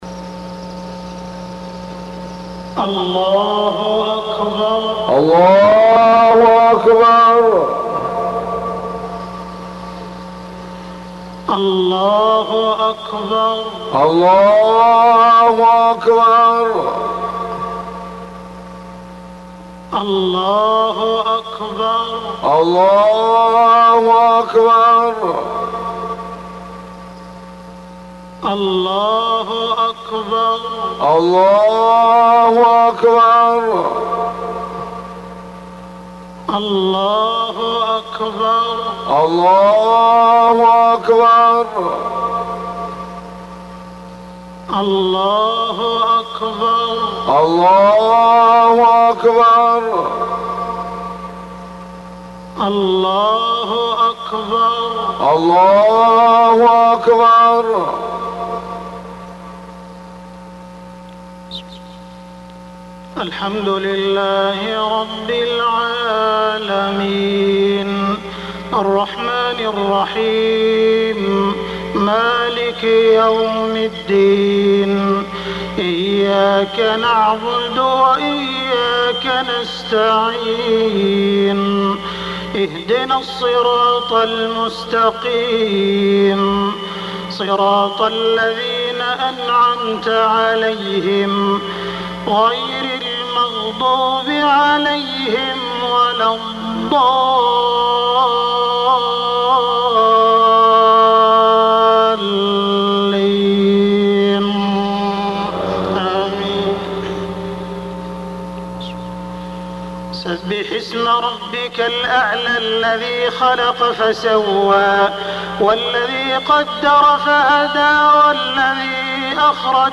خطبة الاستسقاء
تاريخ النشر ٢٢ شوال ١٤٢٧ هـ المكان: المسجد الحرام الشيخ: معالي الشيخ أ.د. عبدالرحمن بن عبدالعزيز السديس معالي الشيخ أ.د. عبدالرحمن بن عبدالعزيز السديس خطبة الاستسقاء